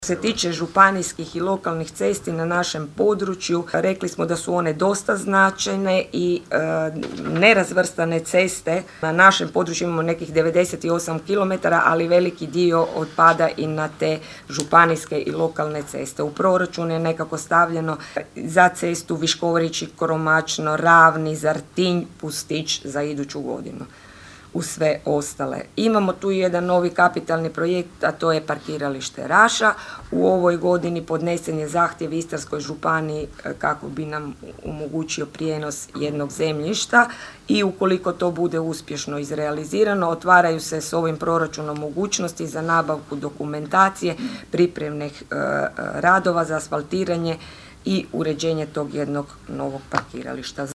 rekla je predstavljajući nacrt prijedloga na sinoćnjoj sjednici Općinskog vijeća